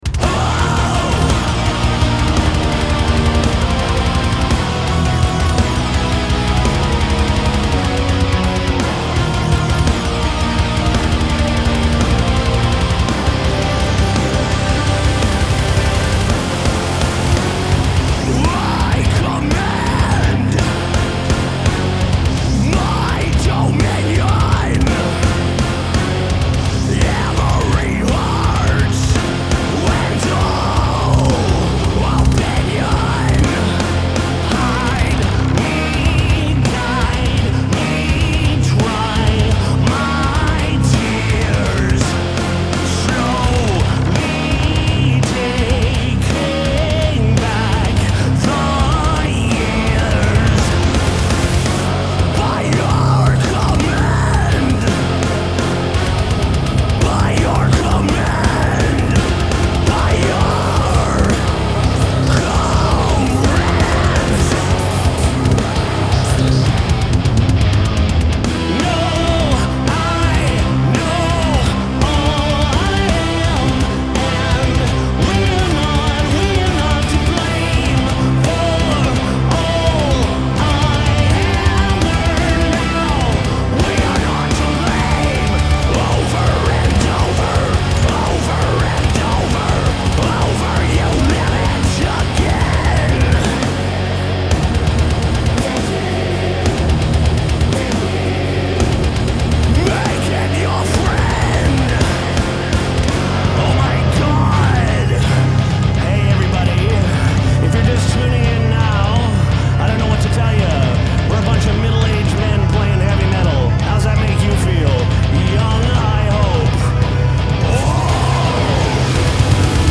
Genre : Progressive Rock, Progressive Metal